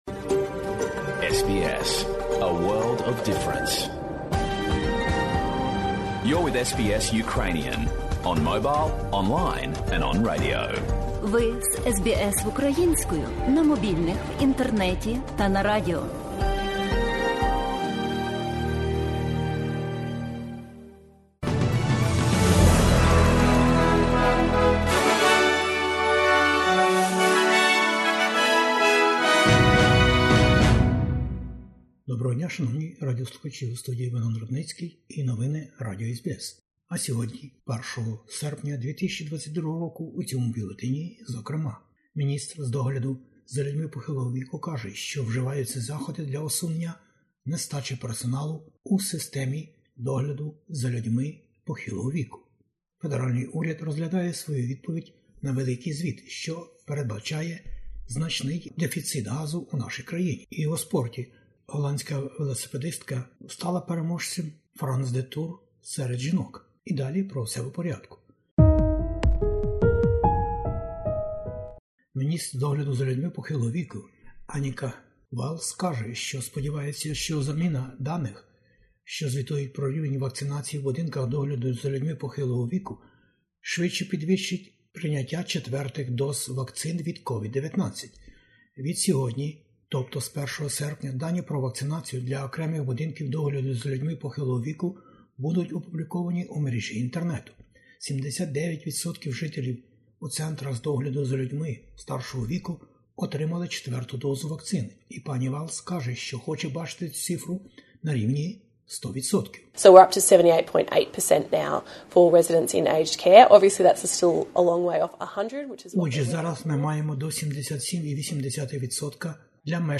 SBS News in Ukrainian - 1/08/2022